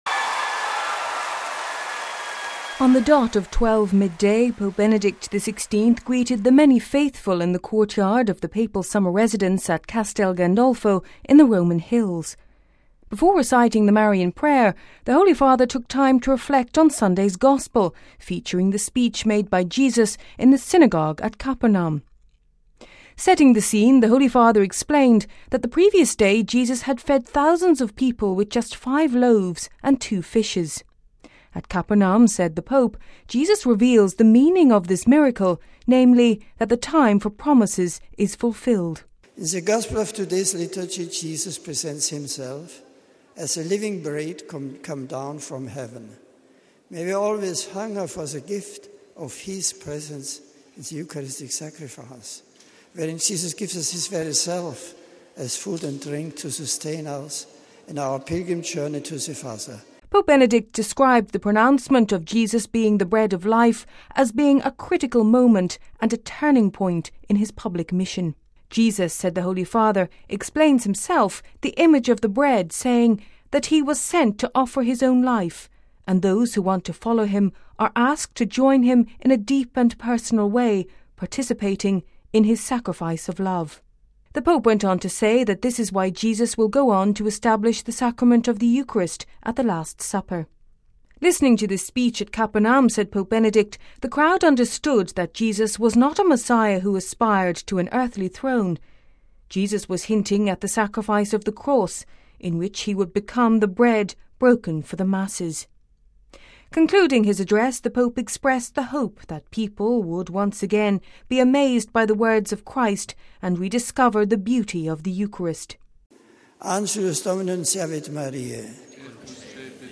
(Vatican Radio) On the dot of twelve midday Pope Benedict XVI greeted the many faithful in the courtyard of the Papal Summer residence at Castelgandolfo in the Roman Hills.